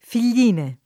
fil’l’&ne] top.